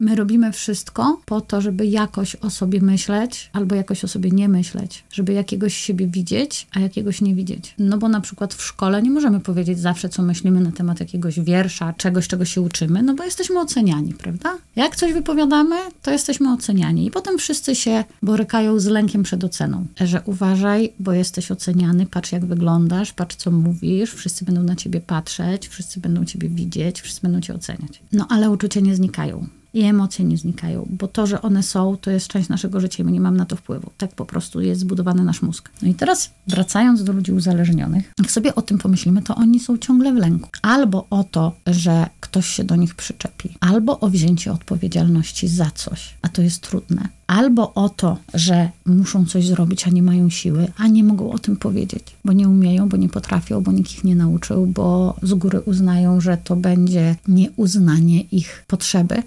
Czy asertywność to tylko umiejętność mówienia ,,nie”? O tym rozmawialiśmy